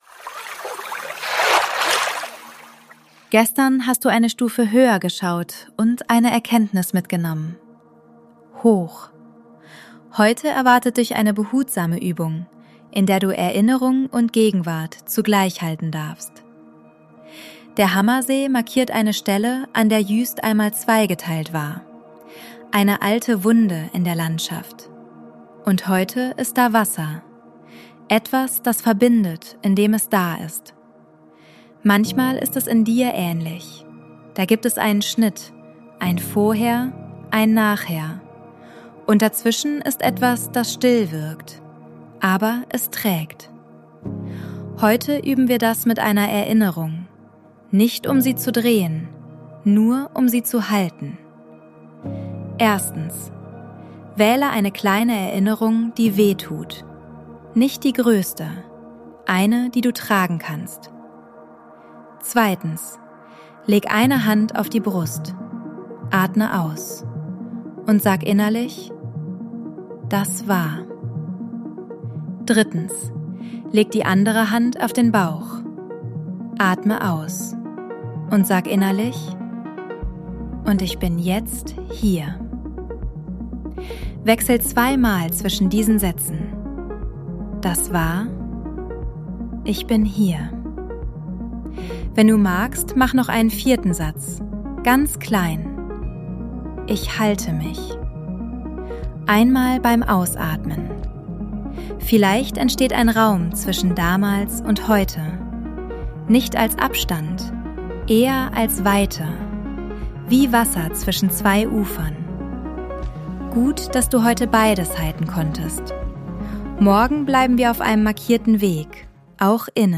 Sounds & Mix: ElevenLabs und eigene Atmos